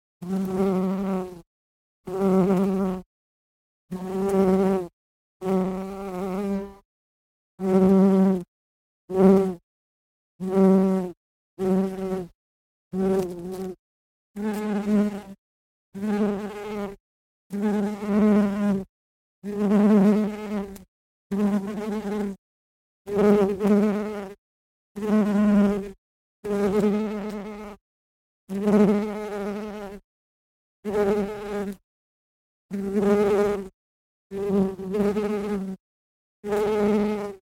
Звуки шмеля_ Жужжание шмеля, когда он летает на короткие расстояния
• Категория: Шмель
• Качество: Высокое